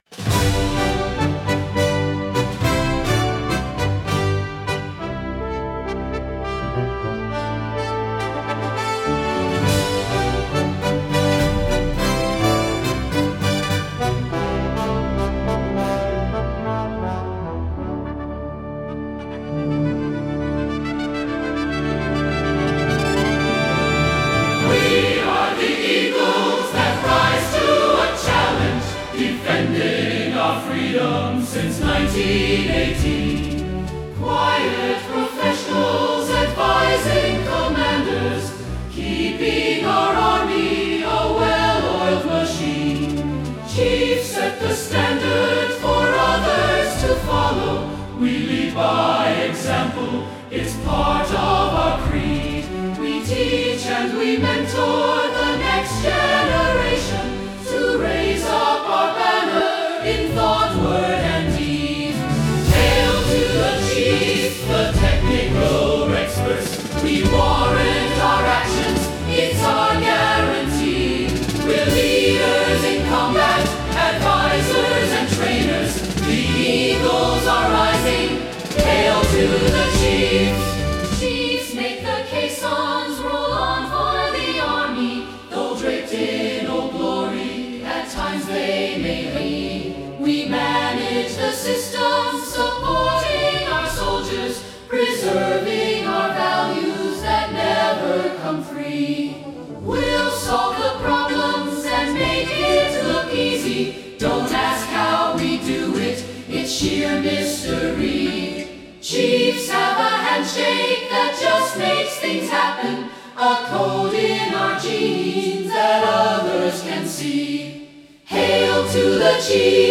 Sung by the HS Knights Templar Choir in May 2019
a Capella